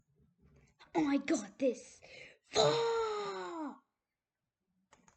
Courage FAH Sound Effect (Child Version)
Category ⚡ Sound Effects